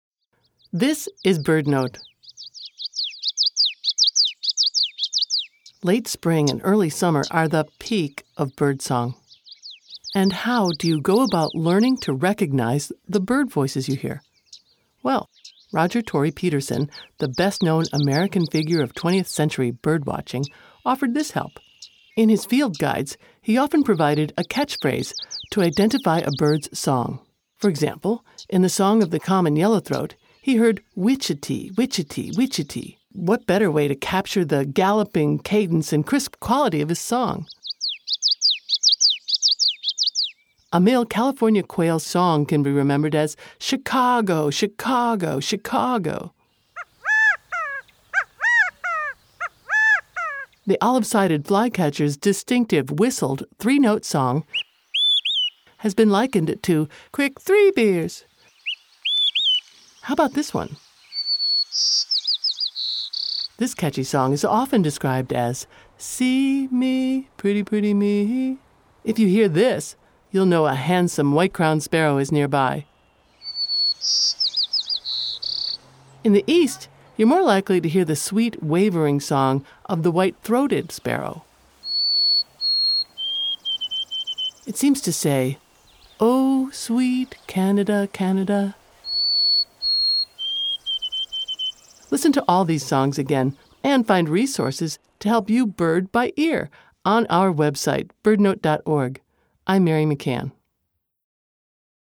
Whenever he could, he provided a catchphrase to identify a bird’s song. “Witchety-witchety-witchety” captures the song of this Common Yellowthroat. The California Quail seems to say, “Chicago, Chicago, Chicago.”